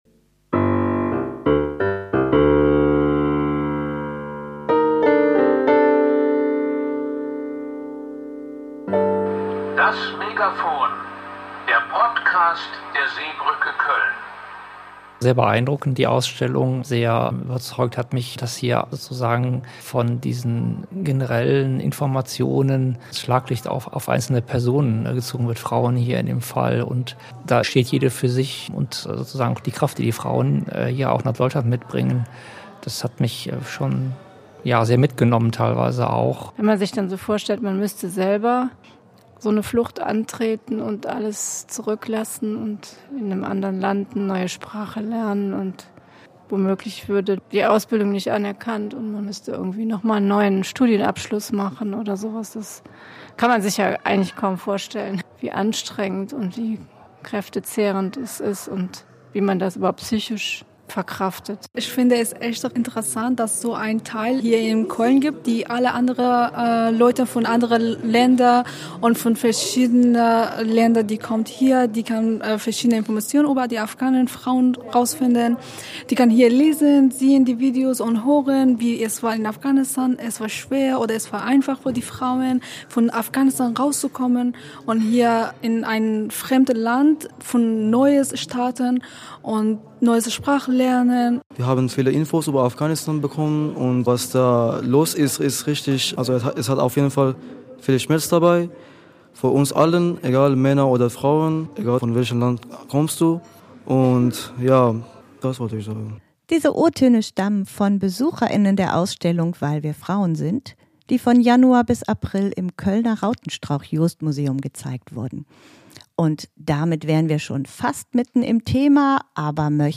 Wir haben 2 Interviews geführt mit Frauen von der Organisation von medica mondiale, die über die eigene Flucht bzw. die Unterstützung der Flucht von Frauen aus Afghanistan berichten. Ebenfalls beleuchten wir wie sich die Bundesregierung derzeit zu dem Thema verhält.